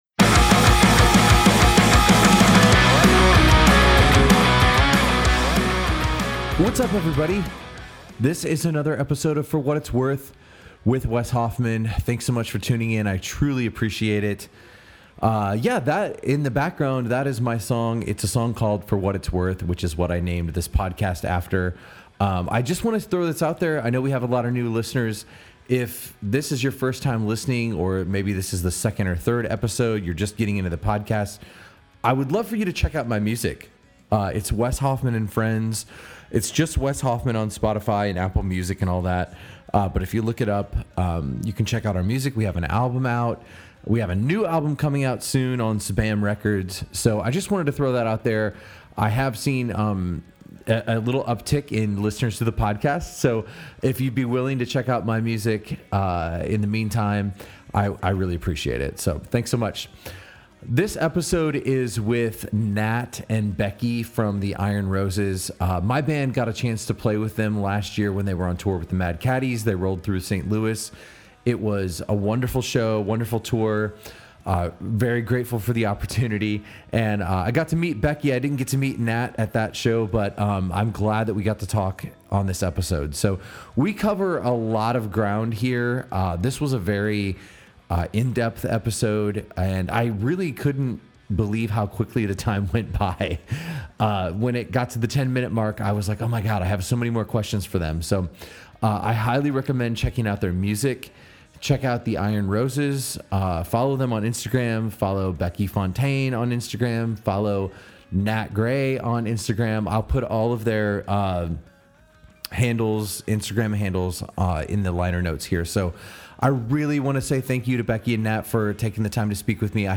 We had a great conversation about how the band has grown over the last few years, their songwriting process, the community around the Roses, and much more.